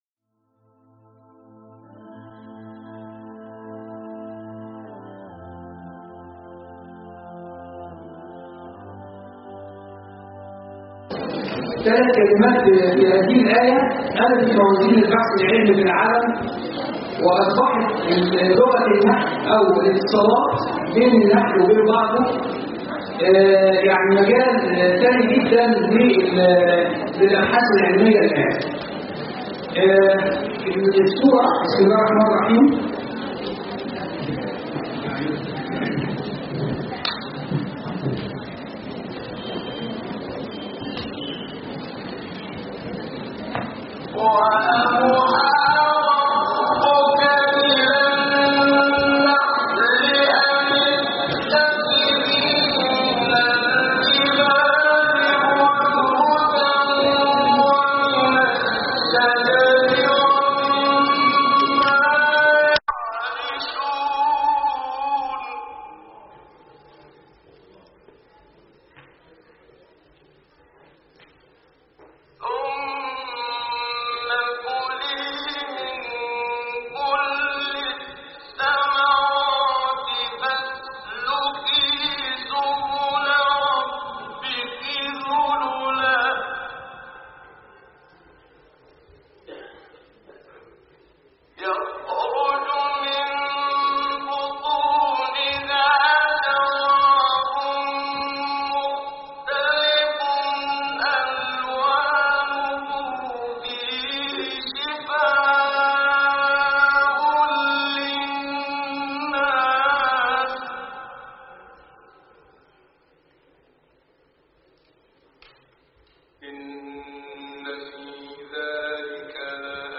كلمة
بالمؤتمر السنوى التاسع للإعجاز العلمى